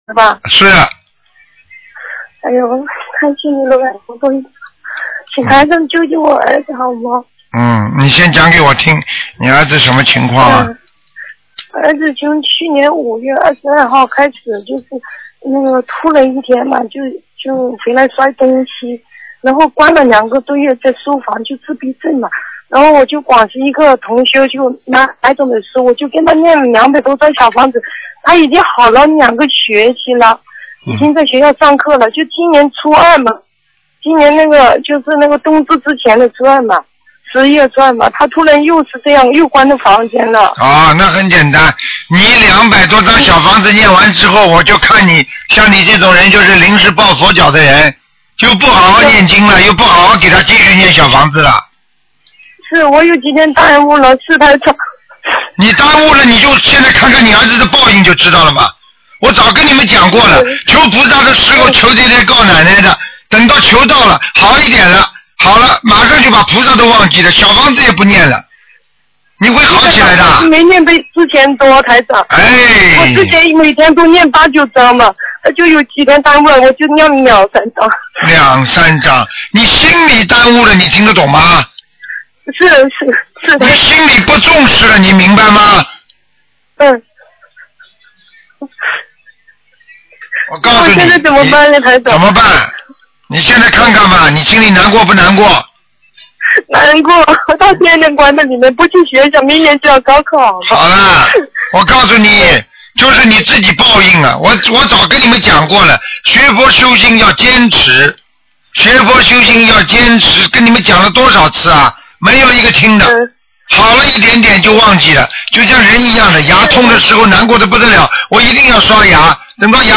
目录：2013年01月_剪辑电台节目录音集锦